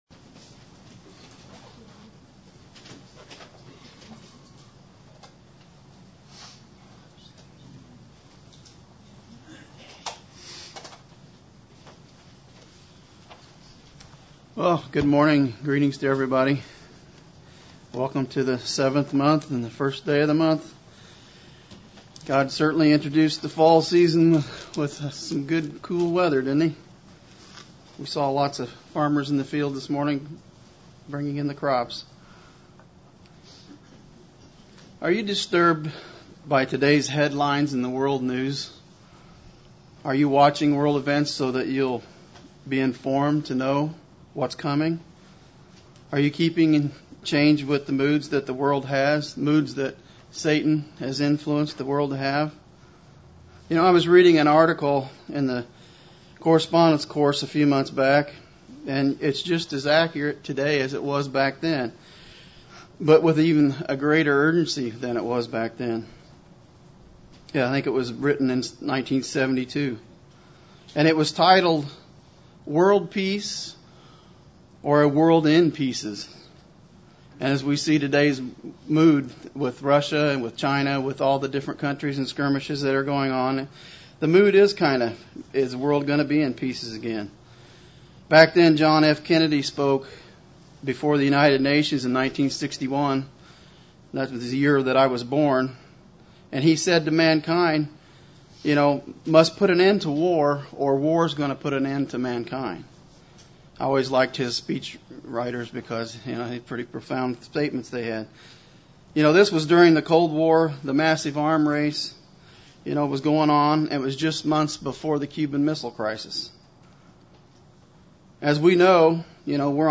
UCG Sermon Studying the bible?
Given in Central Illinois